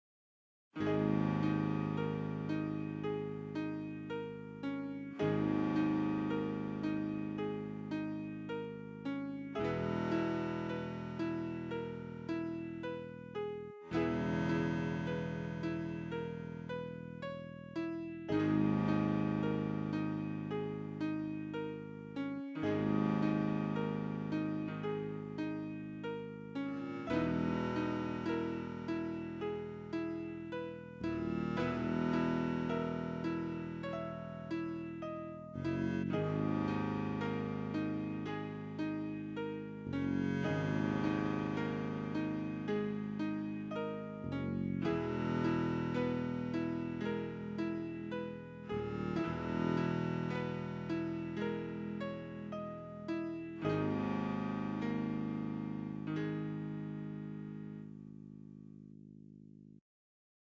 BGM Music